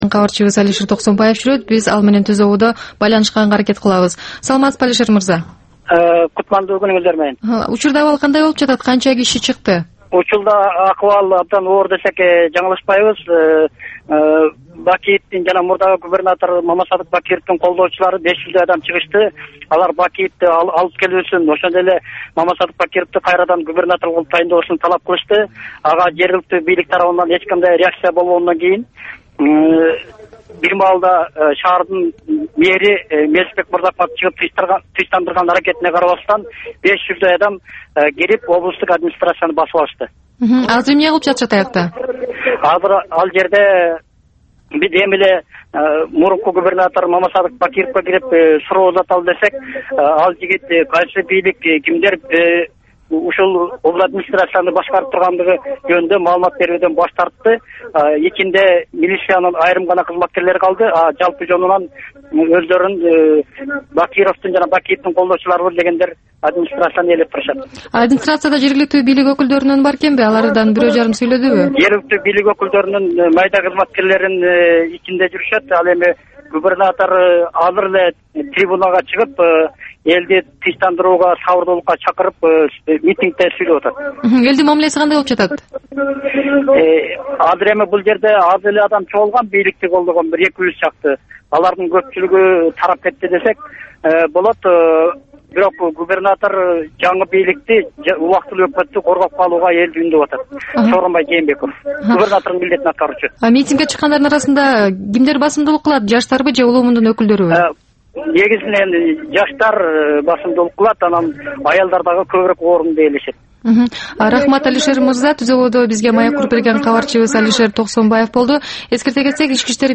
Бул түшкү үналгы берүү жергиликтүү жана эл аралык кабарлар, ар кыл орчун окуялар тууралуу репортаж, маек, талкуу, кыска баян жана башка оперативдүү берүүлөрдөн турат. "Азаттык үналгысынын" бул чак түштөгү алгачкы берүүсү Бишкек убакыты боюнча саат 12:00ден 12:15ке чейин обого чыгарылат.